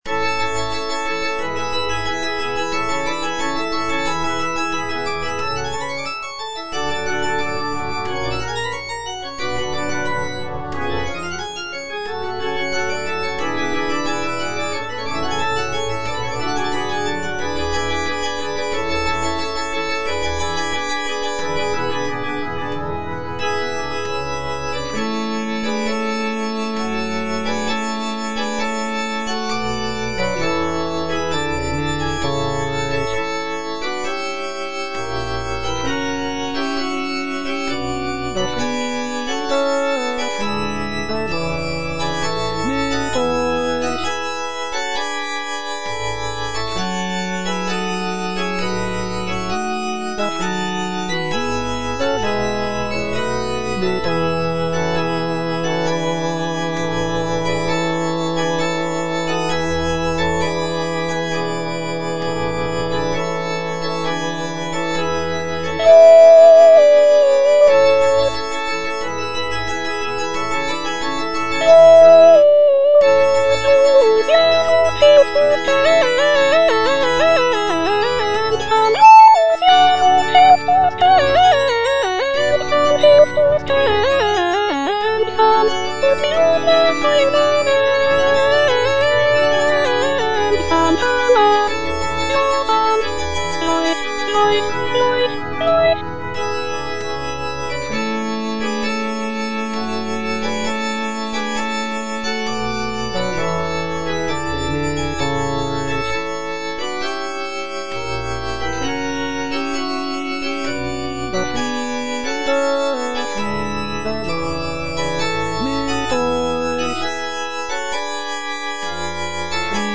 Cantata